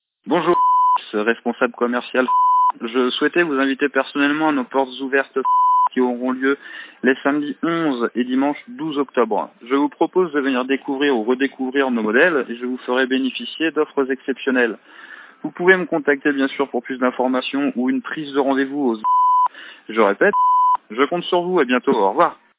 Un mailing vocal, aussi appelé SMS vocal, VMS ou encore Message répondeur, consiste à déposer en masse un message vocal directement sur le répondeur mobile de vos contacts.